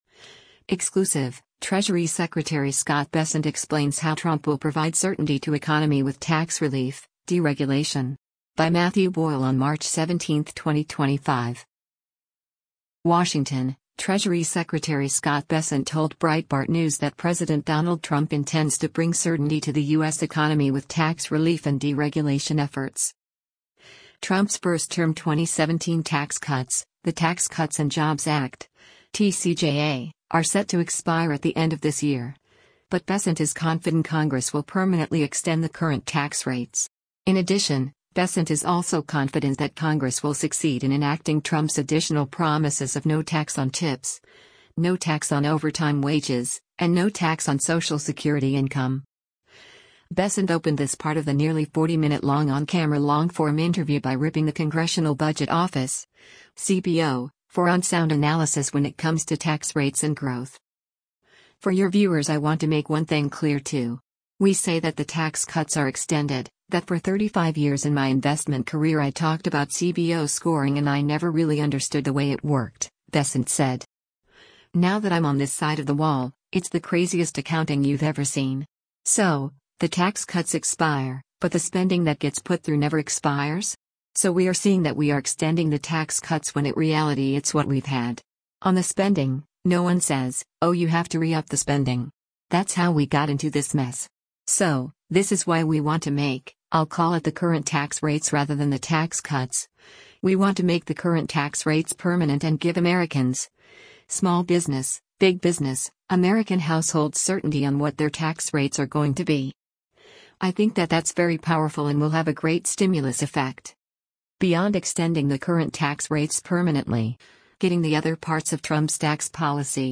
Bessent opened this part of the nearly 40-minute-long on-camera long-form interview by ripping the Congressional Budget Office (CBO) for unsound analysis when it comes to tax rates and growth.
Asked about this criticism, Bessent laughed it off then pointed to GOP unity since the inauguration.